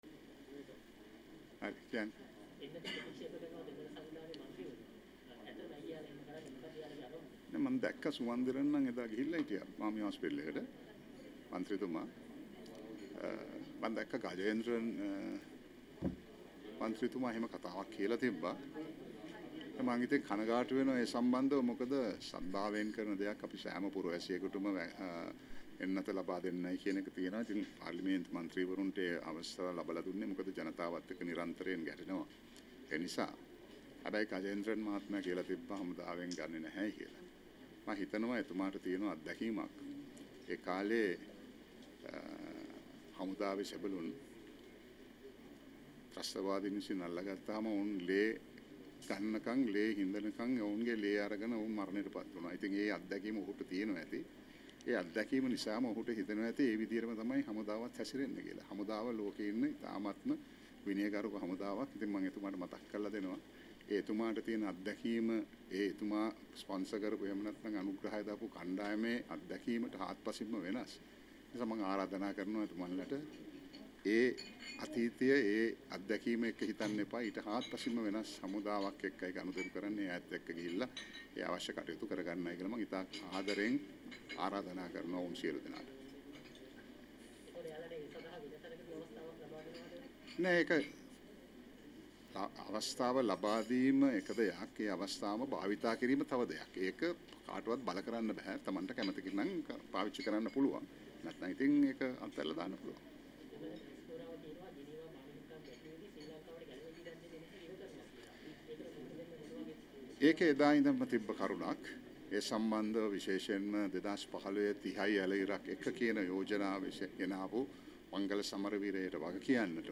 දෙමළ ජාතික සන්ධානයේ ඇතැම් මන්ත්‍රීවරු කොරෝනා එන්නත ලබා ගැනීම ප්‍රතික්ෂේප කරන්නේ යුධ සමයේ දී යුධ හමුදා සෙබළුන්ට ඔවුන් දුන් පීඩාව සිහිපත් වීමෙන් නිසා විය යුතු යැයි මහනුවර අනිවත්තේ දී පැවැති උත්සවයකින් පසු මාධ්‍ය හමුවේ අදහස් දක්වමිනි